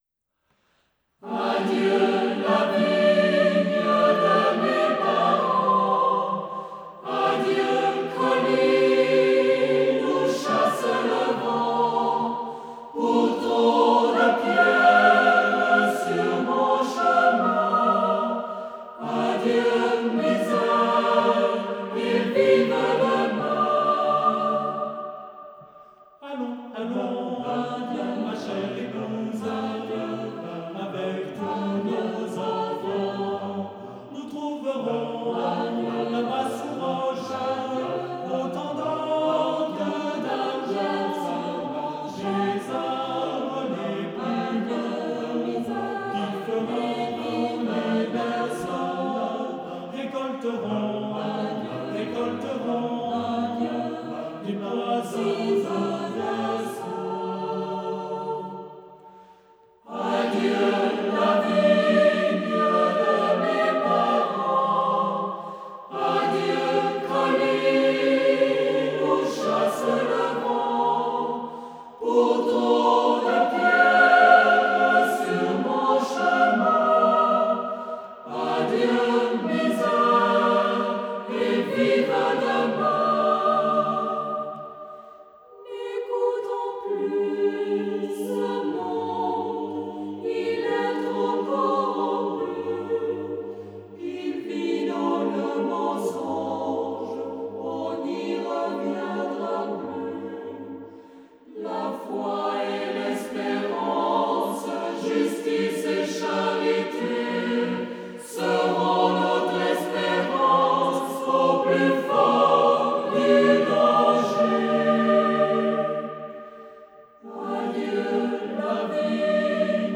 Genre-Stil-Form: weltlich ; Liedsatz
Charakter des Stückes: traurig ; schwungvoll
Chorgattung: SATB  (4-stimmiger gemischter Chor )
Tonart(en): g-moll ; G-Dur